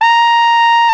Index of /90_sSampleCDs/Roland LCDP12 Solo Brass/BRS_Flugelhorn/BRS_Flugelhorn 1